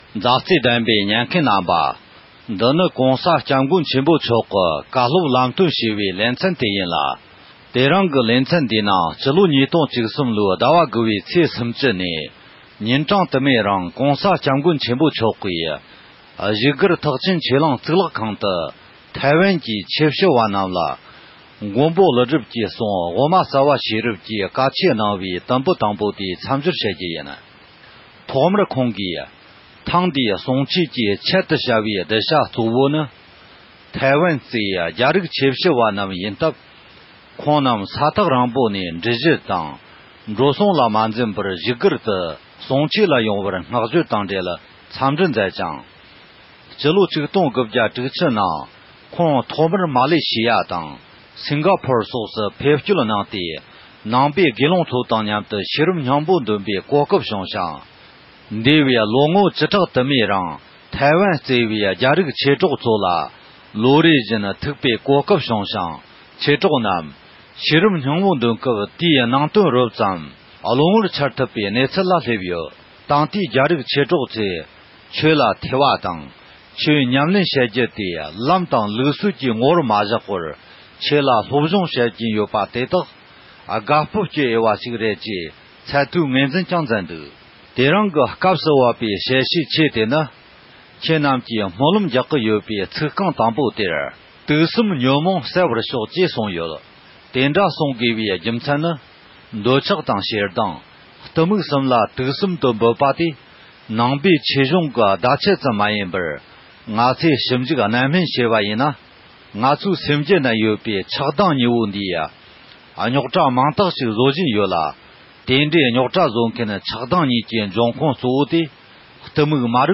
༸གོང་ས་མཆོག་ནས་འདི་ལོའི་ཕྱི་ཟླ་དགུ་པའི་ནང་ཐེ་ཝན་ནས་ཡིན་པའི་དད་ལྡན་ཆོས་ཞུ་བ་ཁག་ཅིག་ལ་དབུ་མ་རྩ་བ་ཤེས་རབ་ཀྱི་ཆོས་འབྲེལ་གནང་ཡོད་པ་རེད།